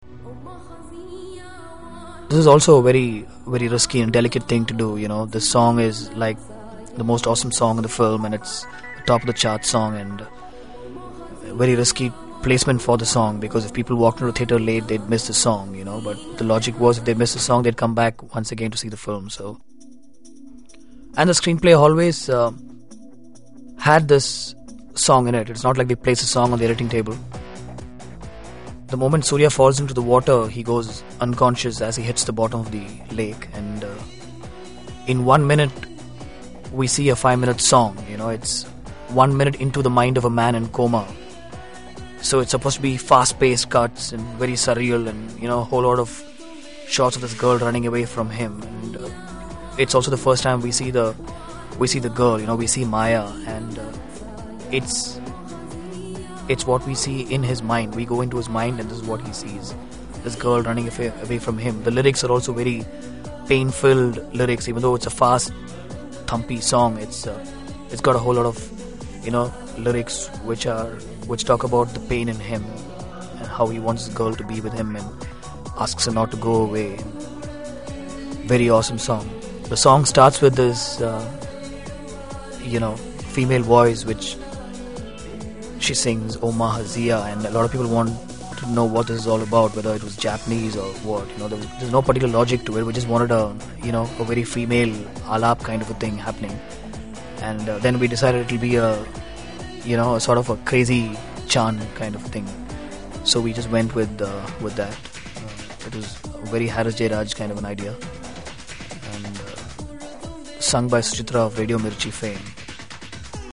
Sample commentary from Kaaka Kaaka